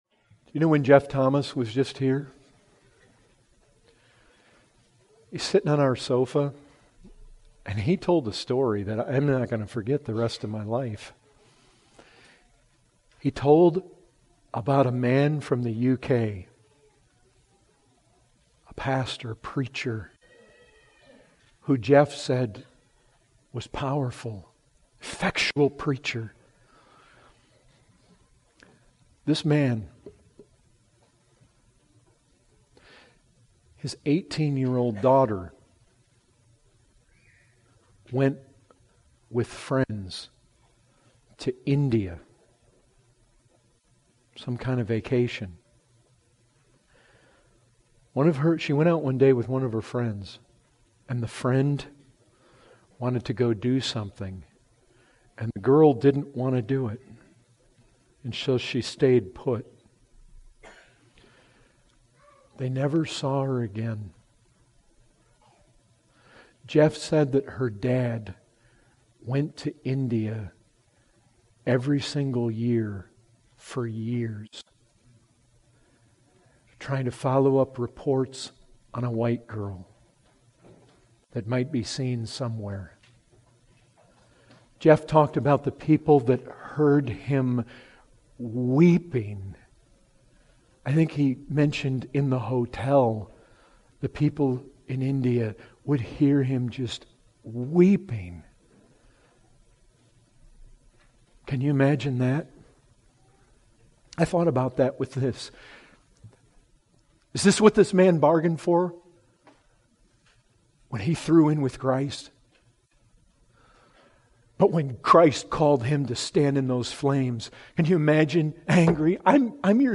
Excerpt taken from the full sermon, “Genuine Saving Faith“.